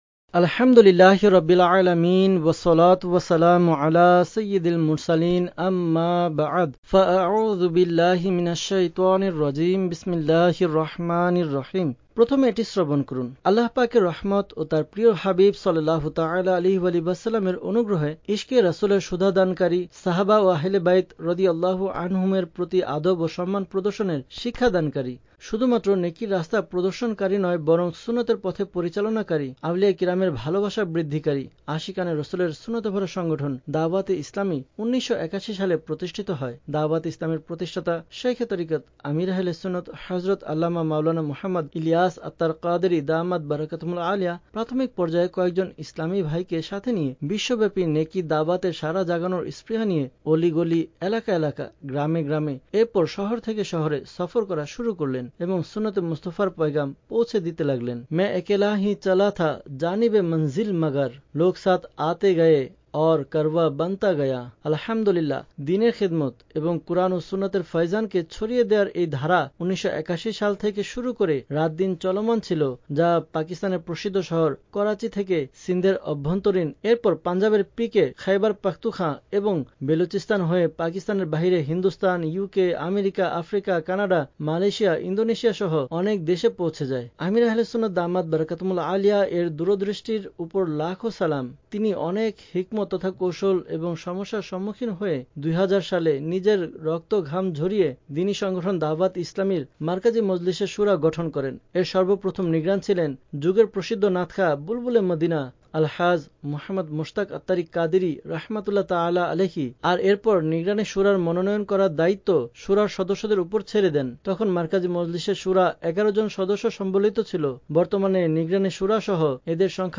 Audiobook - আমীরে আহলে সুন্নাতের লিখনী (Bangla)